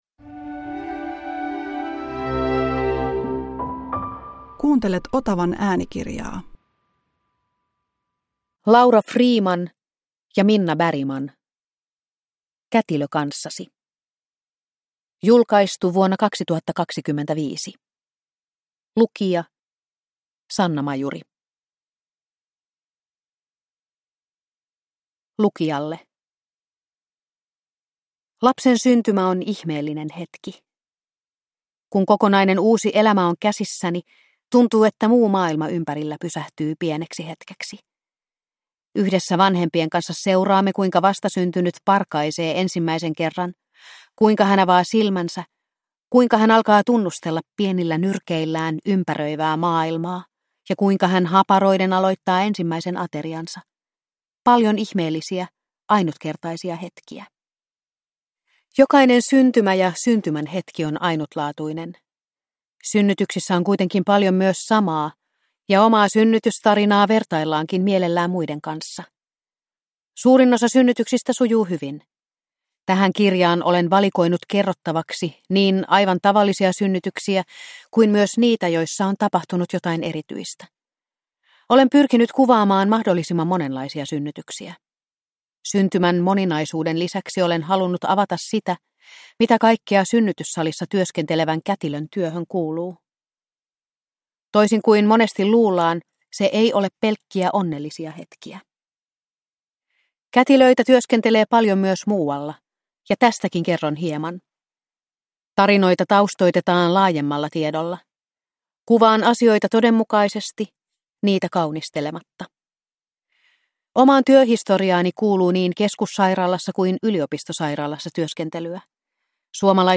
Kätilö kanssasi (ljudbok) av Laura Friman